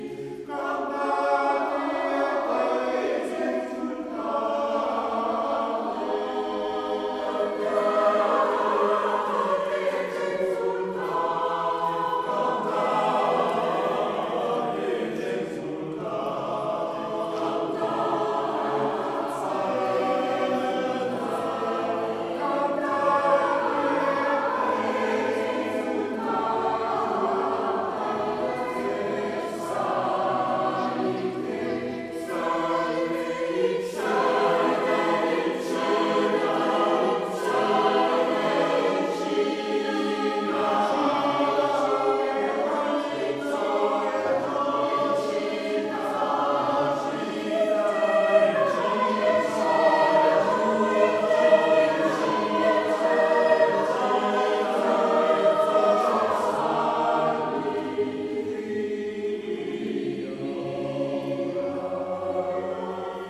Concerts du 21 mai 2022 en l’église Saint Lubin à Rambouillet , 11 juin 2022 en l’église Saint Nicolas de Saint Arnoult en Yvelines et 12 juin 2022 en l’église  Saint Denis de Méré
Chœurs : philMusic et Les Baladins d’Amaury